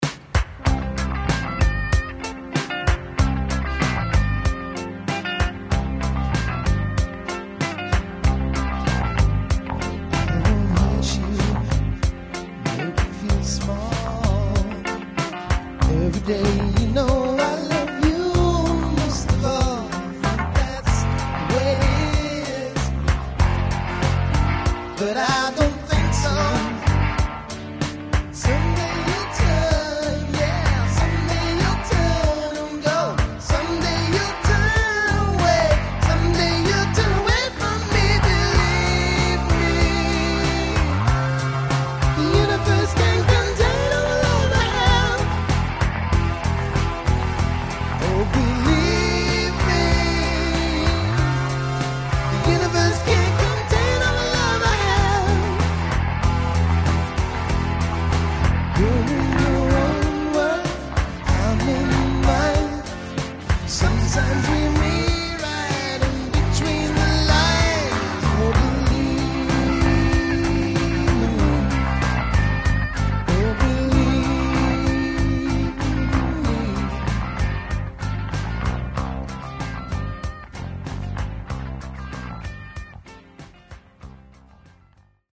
Vocals, Guitars, Keyboards, Bass, Drum Machine